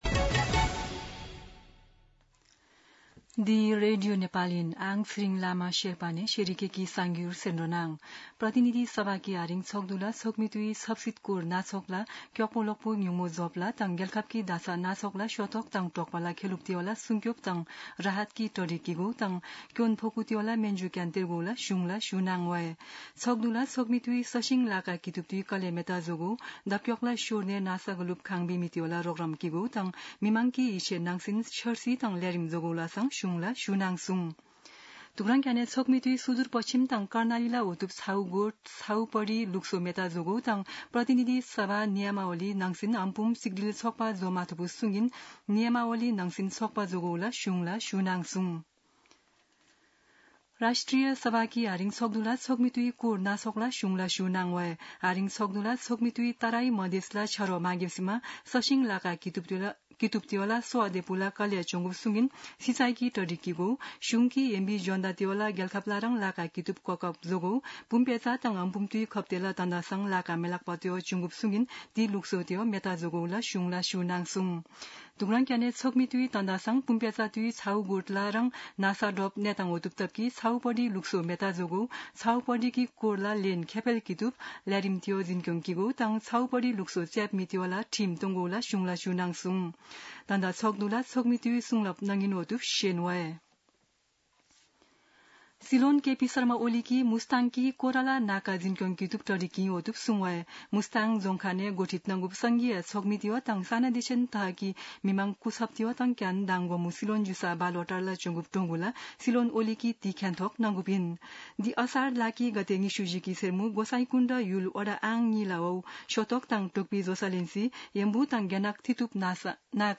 शेर्पा भाषाको समाचार : ३१ असार , २०८२
Sherpa-News-31.mp3